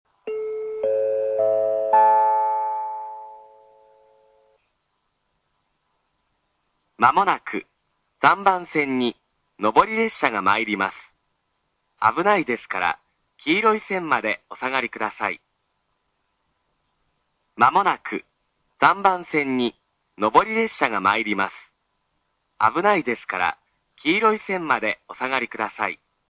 gokan-3bannsenn-sekkinn.mp3